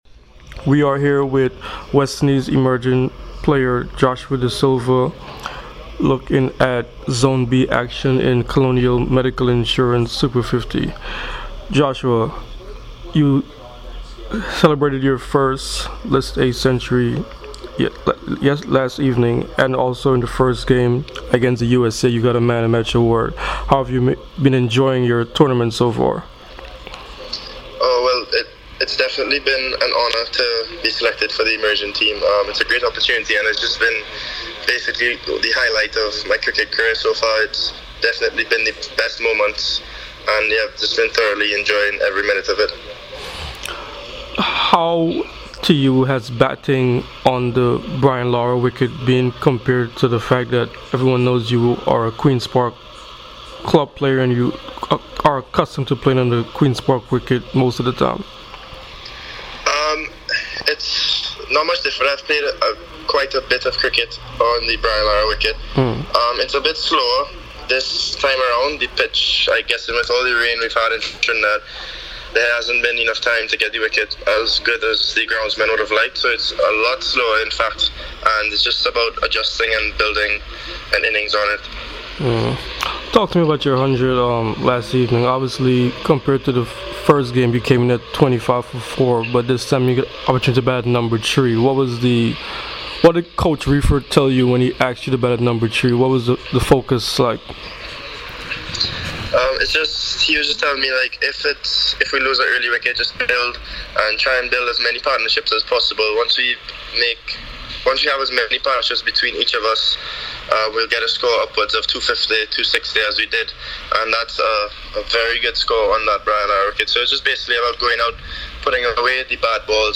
Joshua Da Silva spoke to CWI Media after Zone “B” in the Colonial Medical Insurance Super50 Cup on Monday at Queen's Park Oval and Brian Lara Cricket Academy.